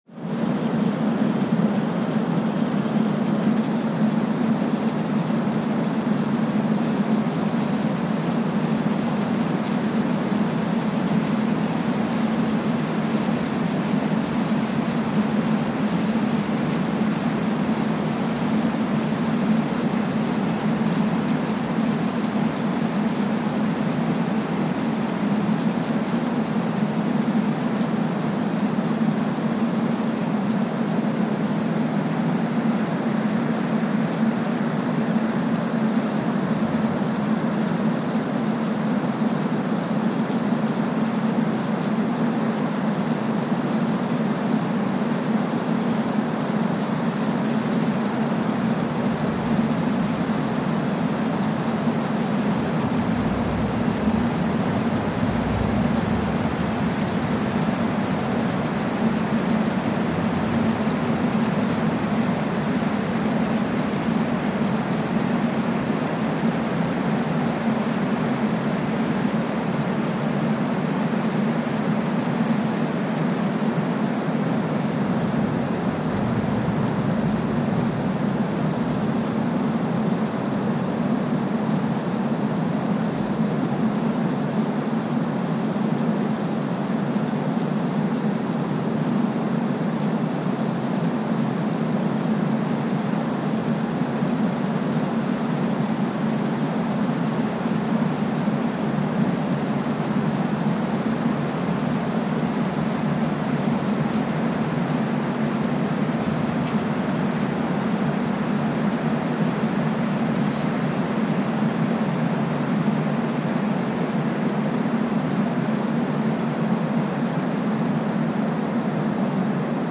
Kwajalein Atoll, Marshall Islands (seismic) archived on June 21, 2023
Sensor : Streckeisen STS-5A Seismometer
Speedup : ×1,000 (transposed up about 10 octaves)
Loop duration (audio) : 05:45 (stereo)